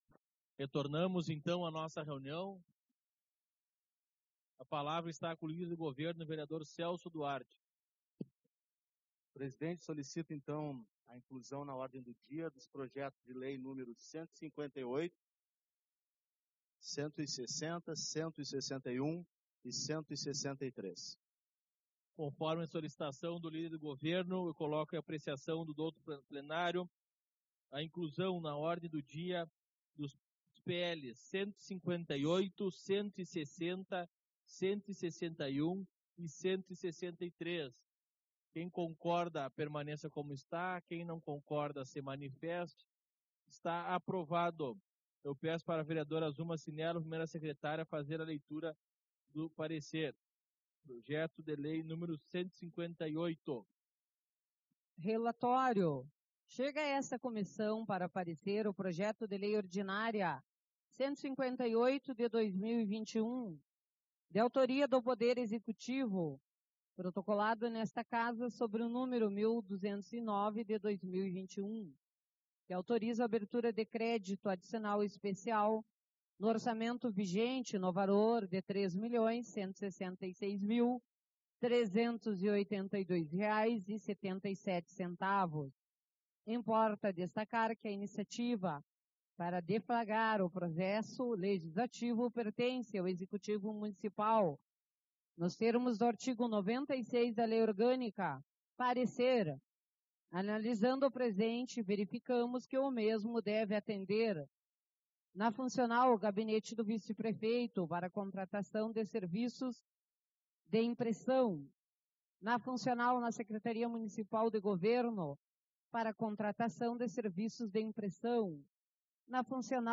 07/12 - Reunião Ordinária